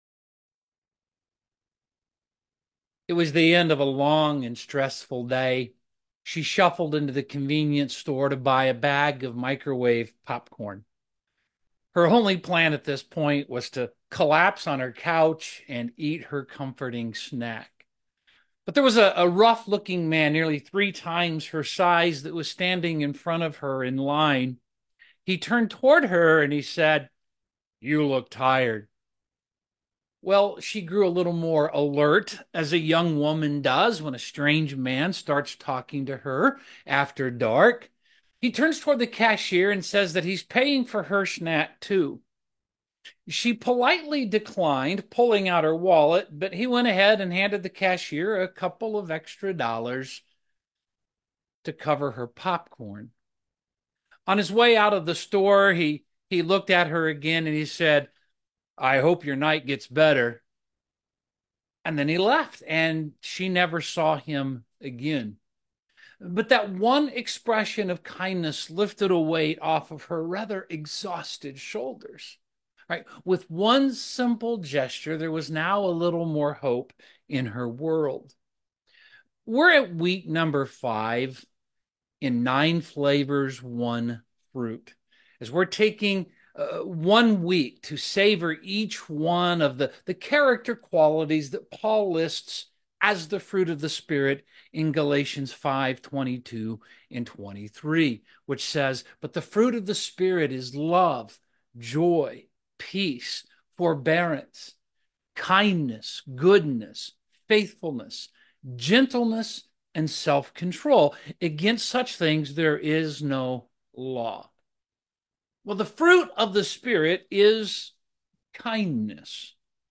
Kindness 9 Flavors 1 Fruit Video Sermon Audio Sermon Save Audio Save PDF What does it mean to be kind?